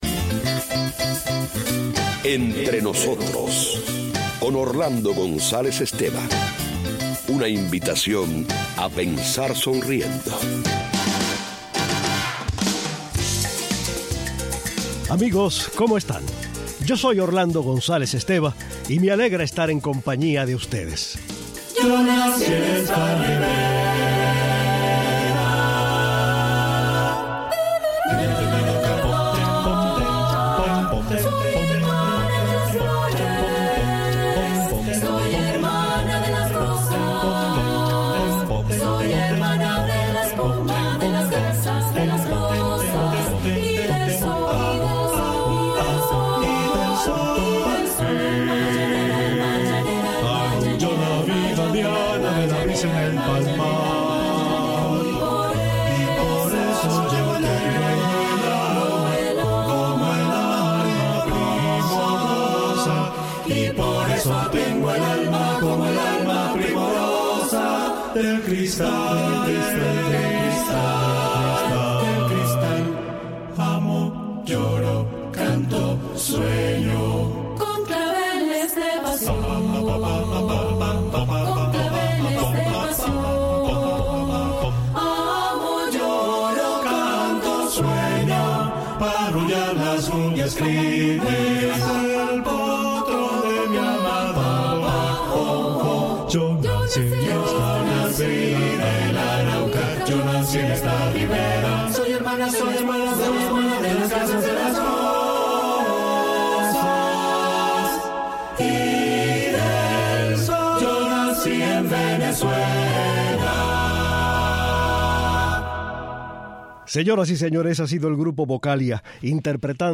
Una velada músical conmueve hasta las lágrimas a la concurrencia y demuestra el poder de un instrumento de cuerdas.